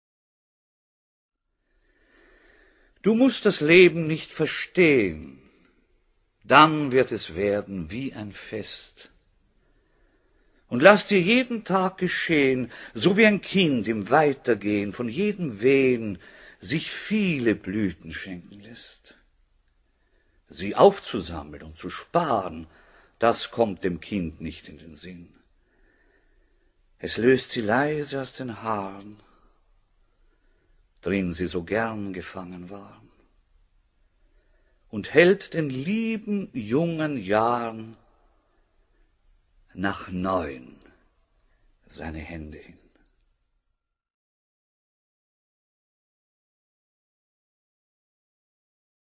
This is from a reading of the great Viennese actor Oskar Werner.
du-musst-das-leben-nicht-verstehen-rilke-oskar-werner.mp3